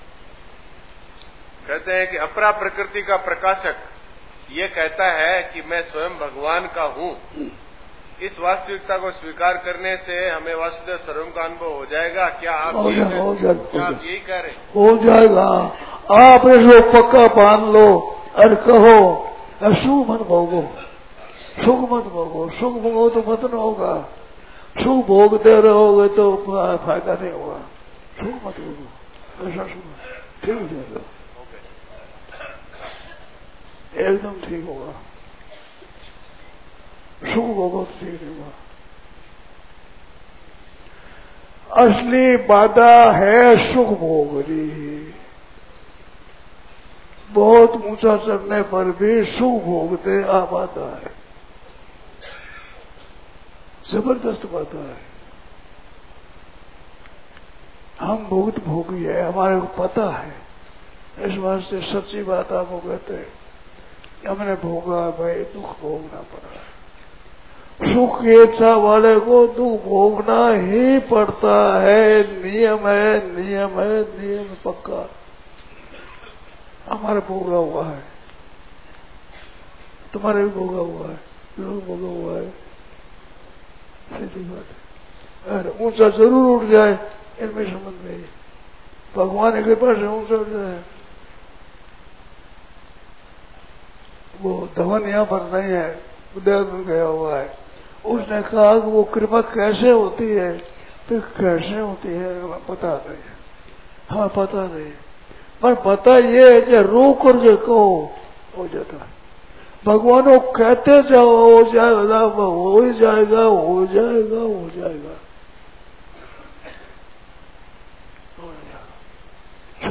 Audio - Question and Answers
in Swamiji’s Voice